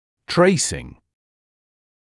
[‘treɪsɪŋ][‘трэсин]сравнительная обрисовка необходимы элементов зубо-челюстной системы для цефалометрического анализа